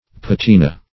patina \pat"ina\ (p[a^]t"[i^]*n[.a]; It. p[aum]"t[-e]*n[.a]), n.